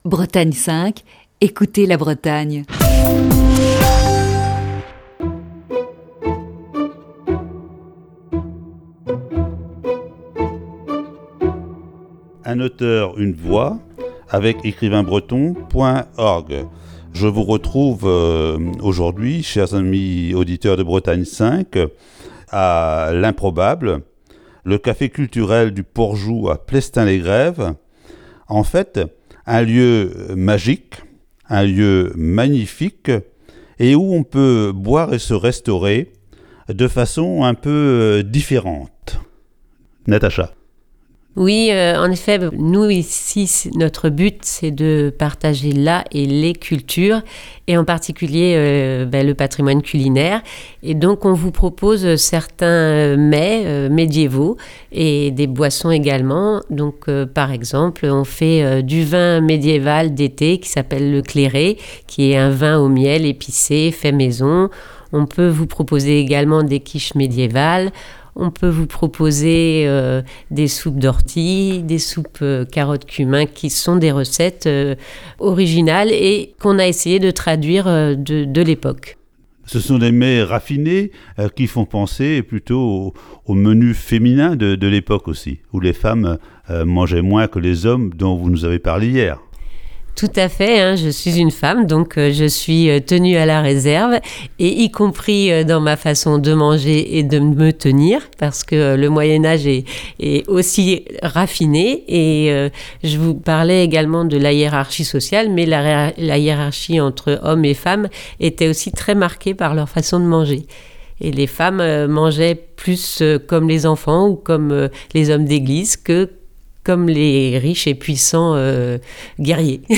Ce vendredi, voici la cinquième et dernière partie de cette série d'entretiens.